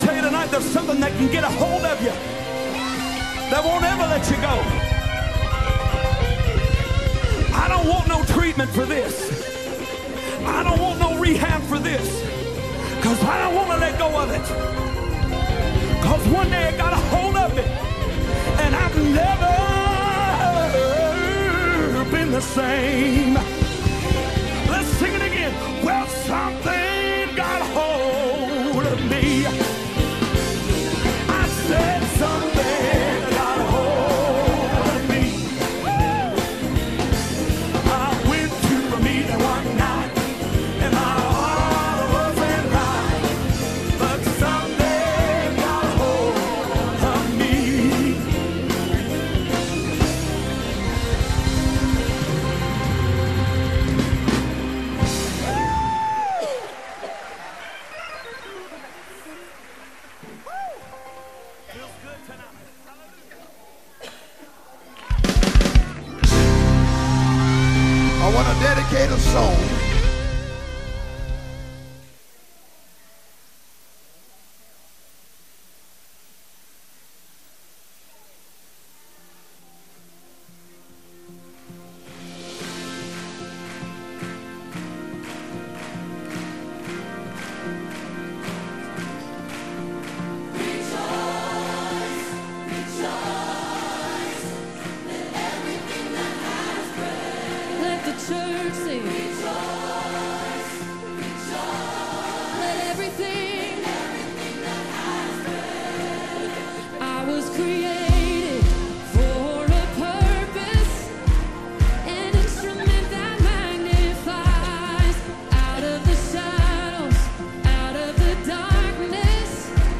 We Pray today that you are Blessed as Five different Preachers Dive into the Unity of The Body Of Christ.